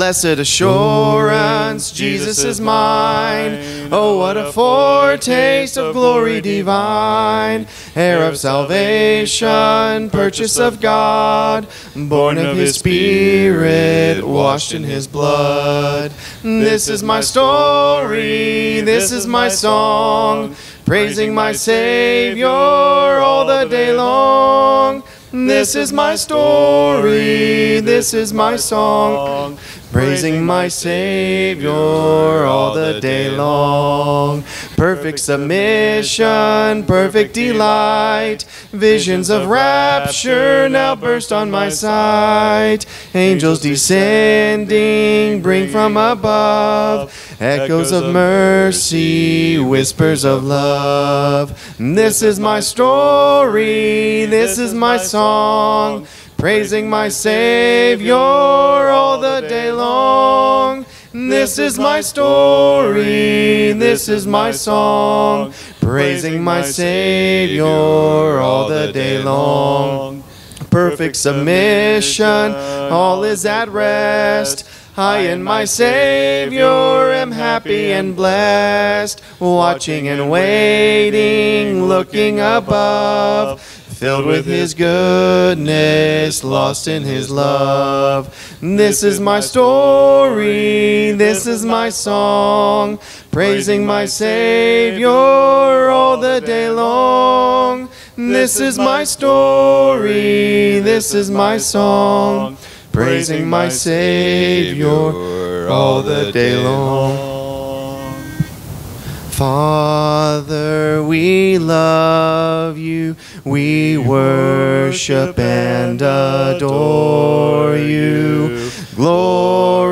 Matthew 5:13 Psalm 37:4 Philippians 4:4-9 Hebrews 10:24 -25 Acts 20:35 Colossians 3:1 Ephesians 2:9 Sermon Audio: Download